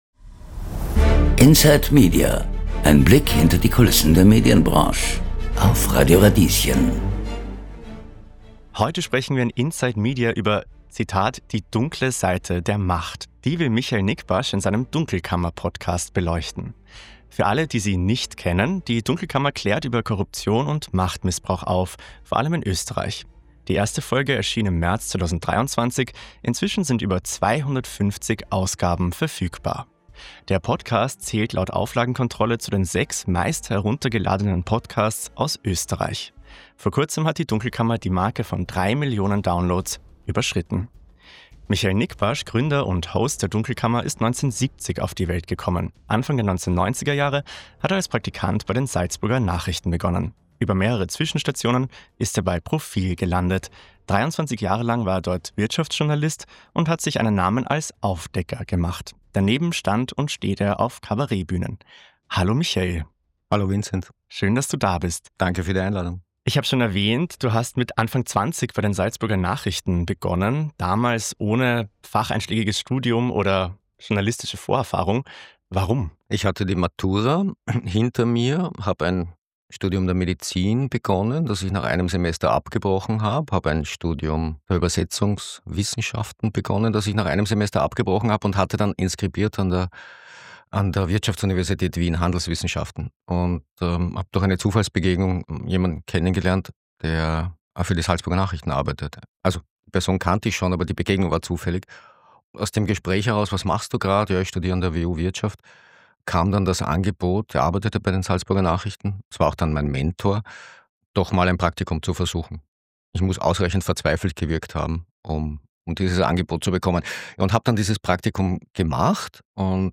Dieser Podcast ist ein Ausschnitt aus der Inside Media-Radiosendung vom 14. November 2025 auf Radio Radieschen.